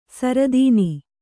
♪ saradīni